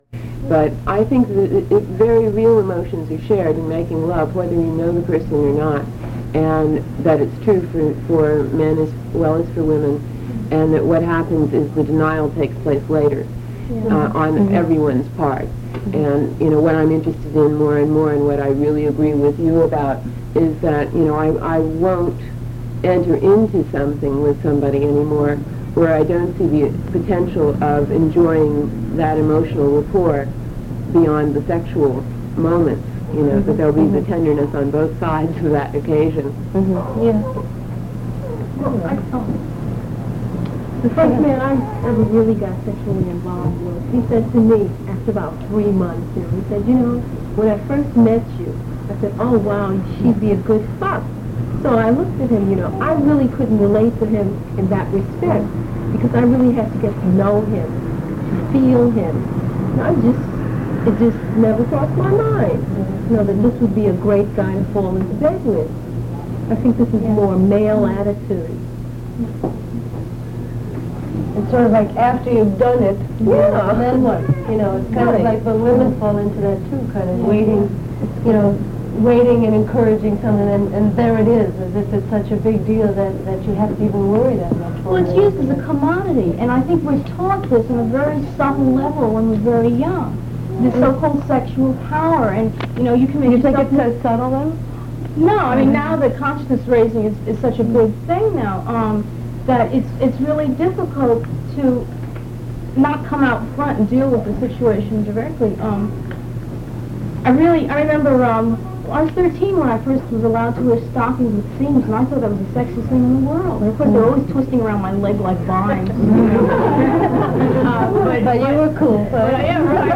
Download File Download File Title Know Your Body: Sexuality Subject Sexuality Body Description From a series of 8 lectures which comprised a course by and for women also entitled "Know Your Body." The course was given at the Women's Medical Center in New York City, where this lecture was recorded for WBAI in May and June, 1972,